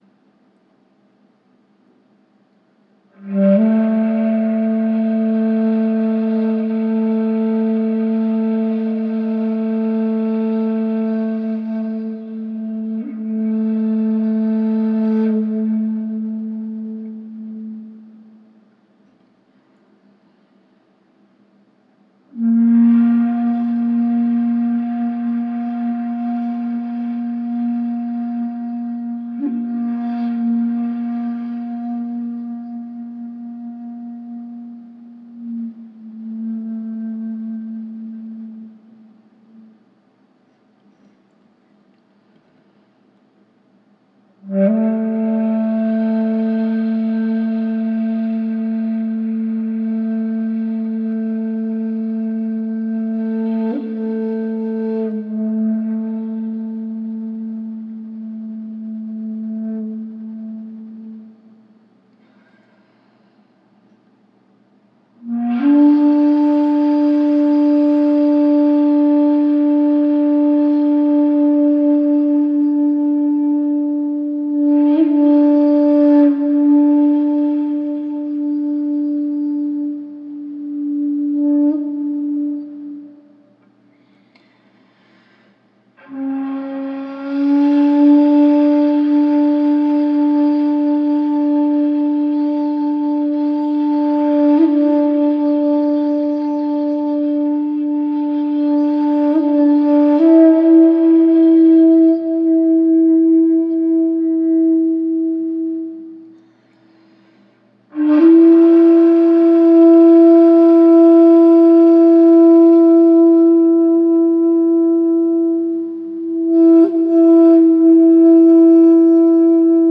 101019-kyo_choshi_yamato_choshi_hifumi_3.1h.mp3 Kyo Choshi, Yamato Choshi, Hi Fu Mi (Хотику 3,1)